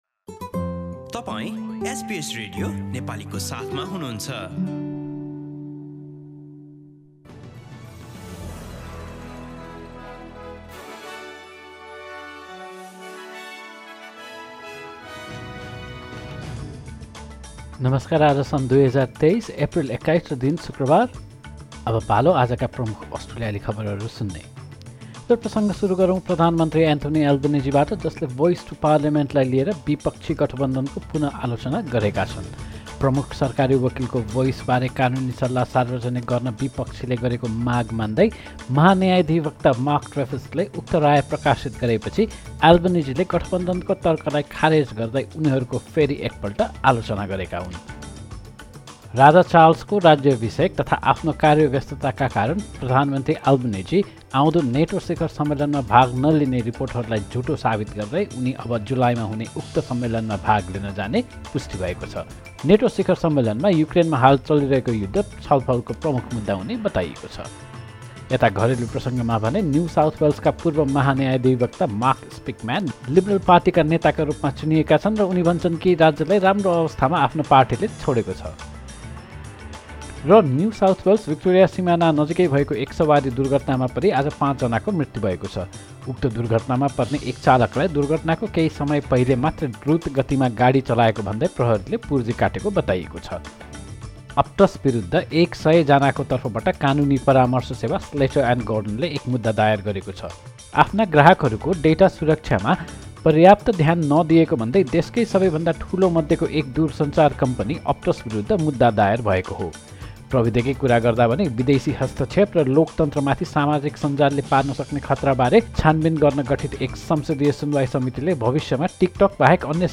एसबीएस नेपाली प्रमुख अस्ट्रेलियाली समाचार : शुक्रवार, २१ एप्रिल २०२३